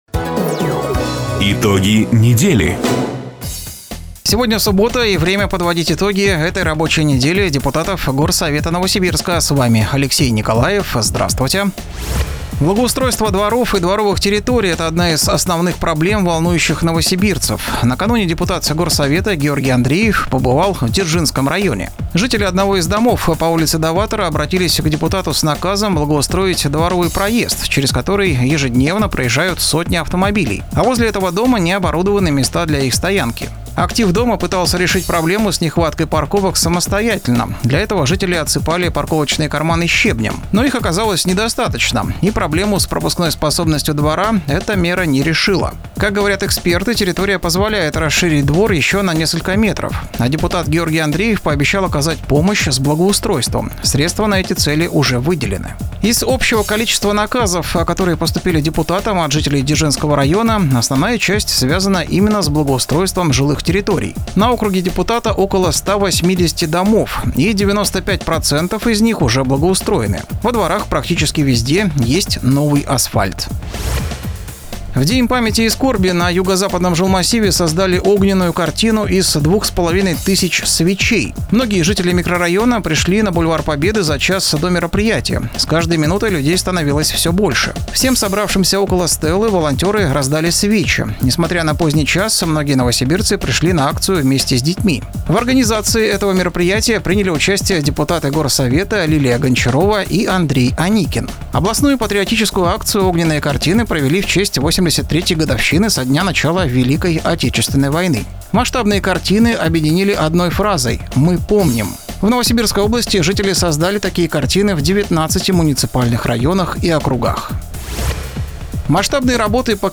Запись программы "Итоги недели", транслированной радио "Дача" 29 июня 2024 года.